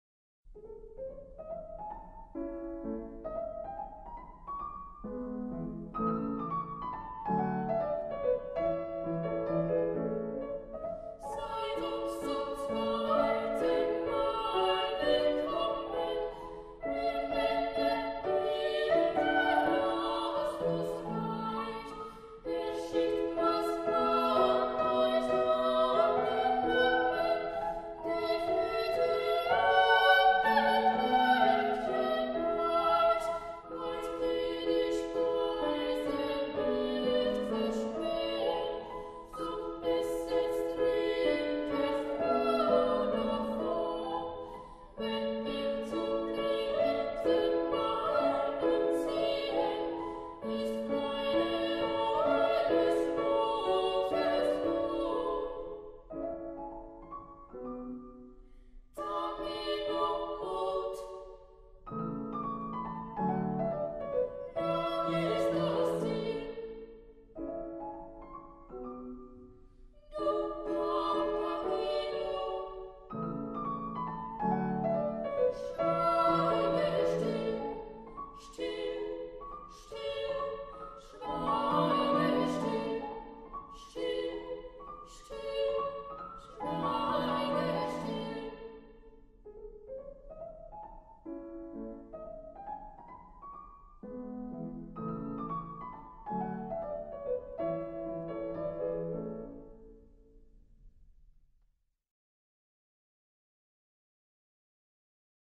类型：古典
五百多年来，经历了战争、瘟疫，甚至哈布斯堡王朝的覆灭，唯一不变的是他们纯净无垢天使般的歌声。